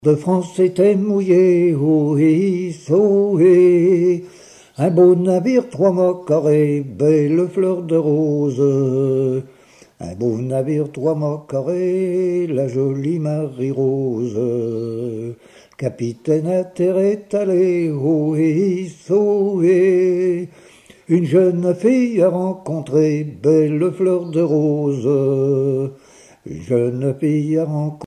gestuel : à virer au cabestan
circonstance : maritimes
Genre laisse
Pièce musicale éditée